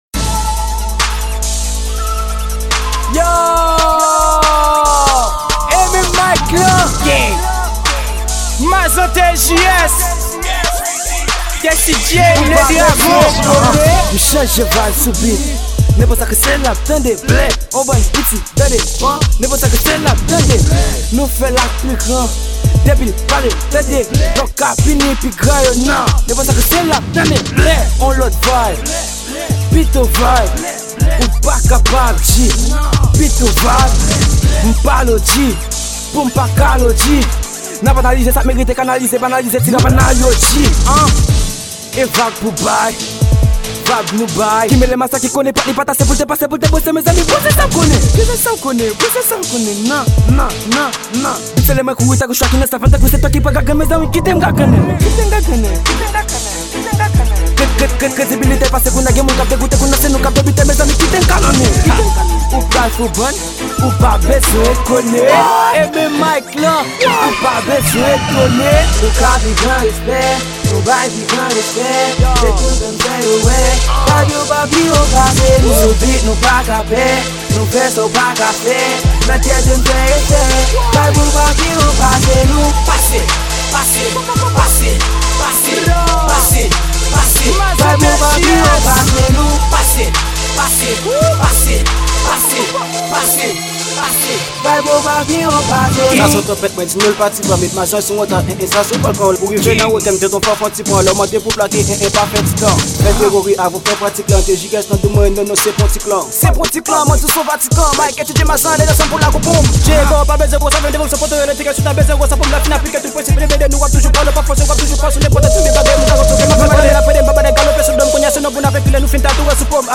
Genre: RAP`.